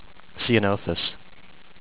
see-an-OH-thus